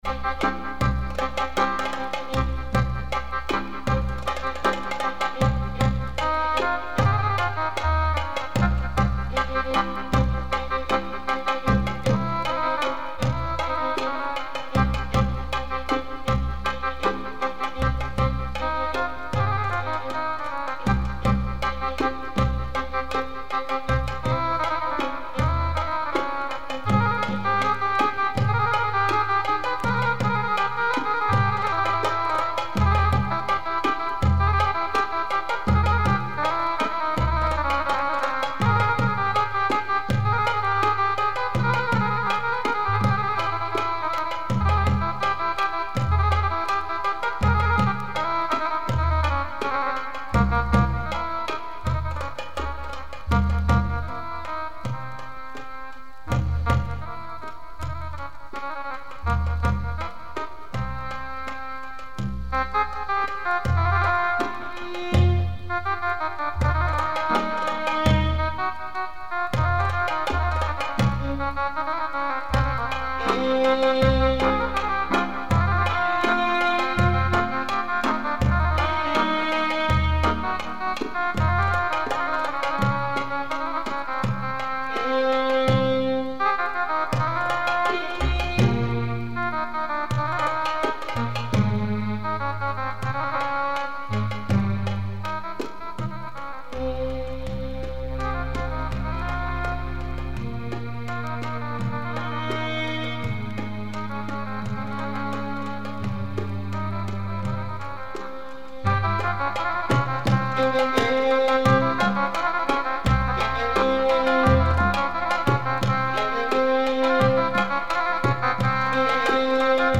belly dance music